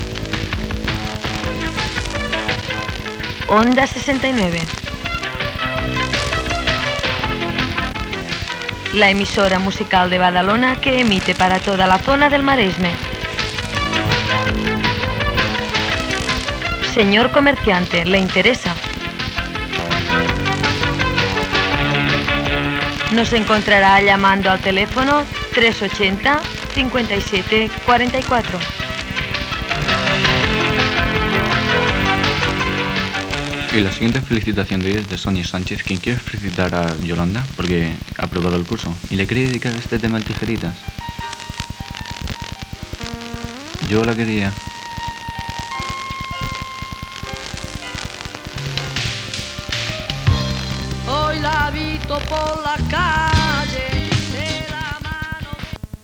0fee9cf6e5b9869db4848ca55fb716ae5c1316b4.mp3 Títol Radio Onda 69 Emissora Radio Onda 69 Titularitat Tercer sector Tercer sector Comercial Descripció Publicitat per captar anunciants i presentació d'un tema musical.
Banda FM